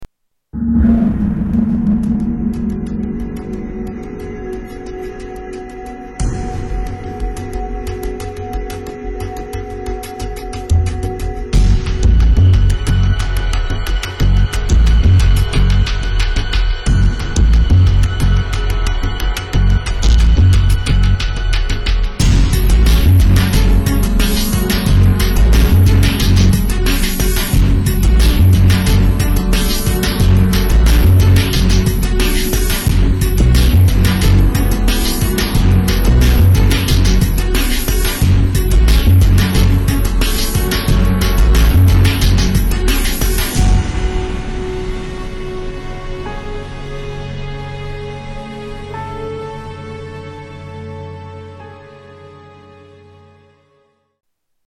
Tags: Sound effects Espionage music Espionage Stealth Music